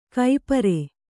♪ kai pare